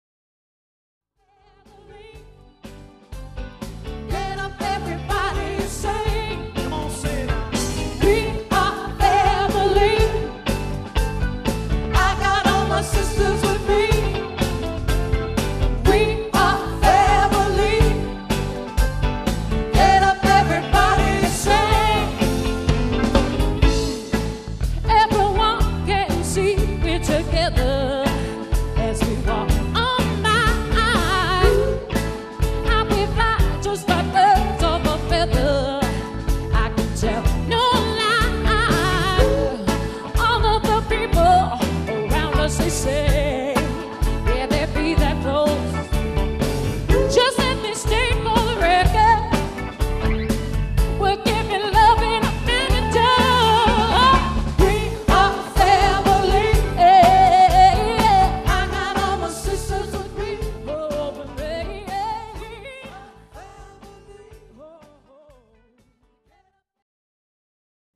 70's Music
Adds A Female Vocalist